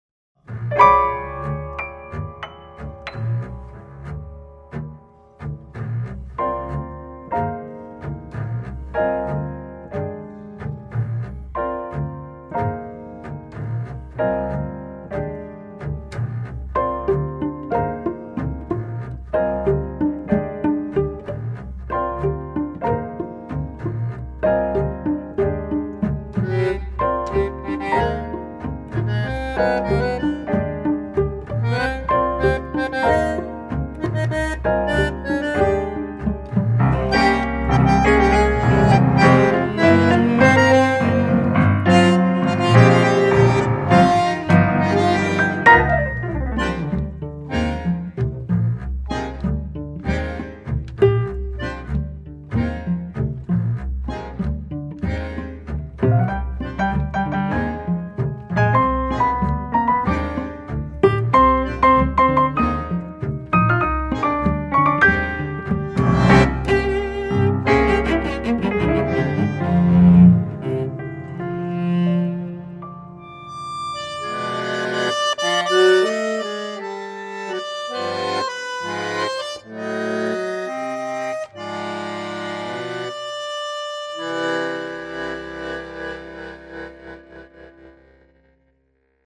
Bandoneon
Violoncello
Contrebasse
Piano